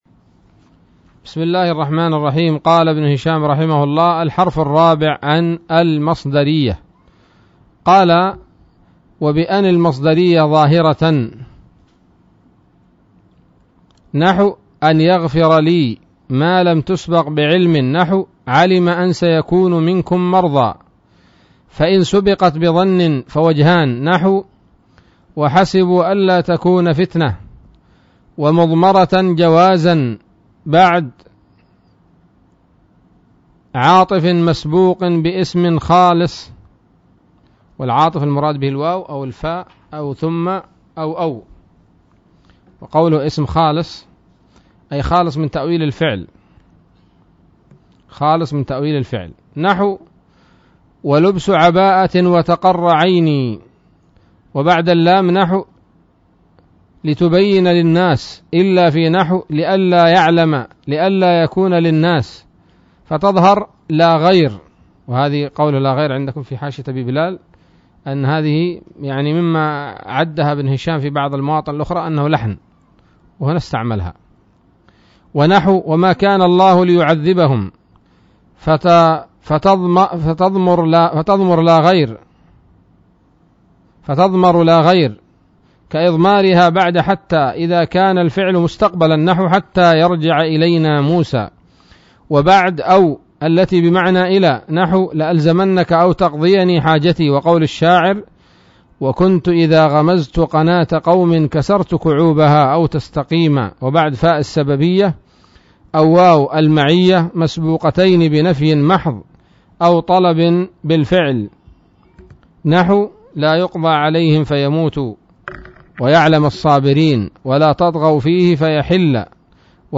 الدرس الثلاثون من شرح قطر الندى وبل الصدى